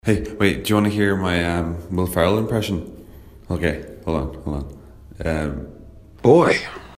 Celebrity Impression
Tags: interview